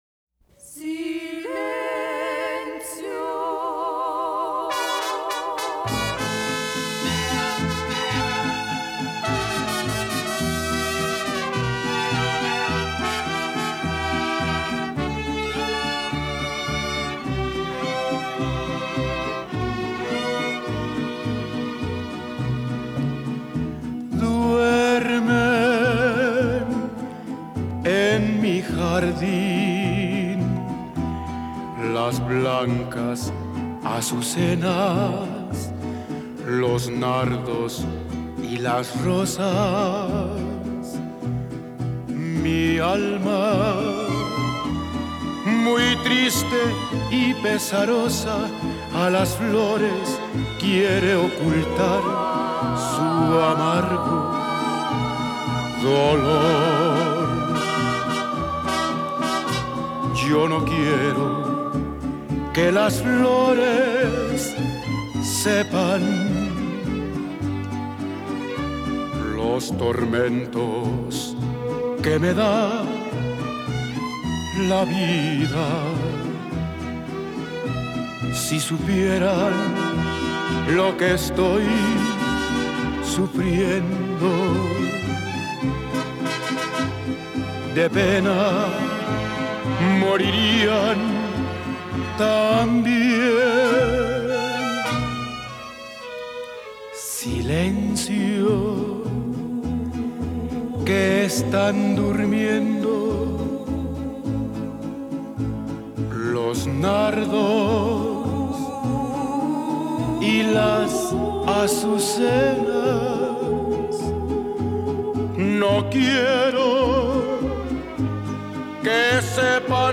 un viaje a la isla del encanto de la mano de un mariachi